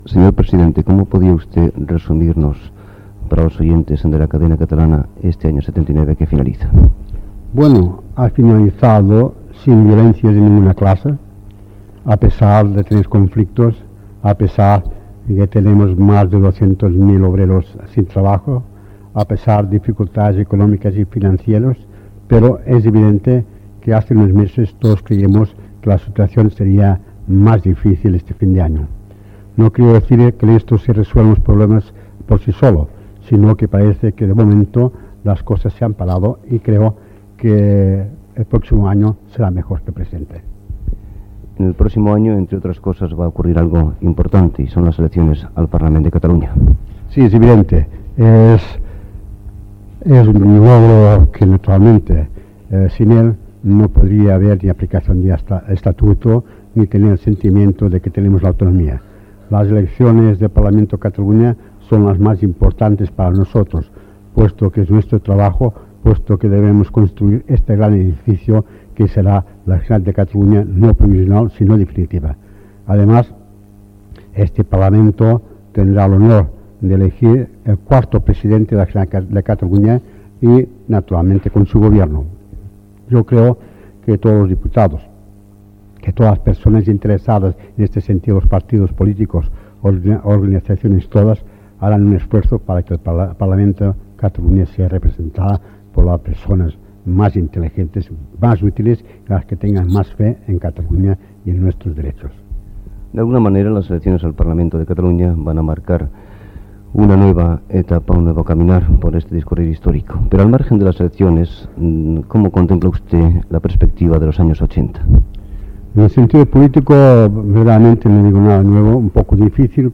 Entrevista de cap d'any al president de la Generalitat, Josep Tarradellas..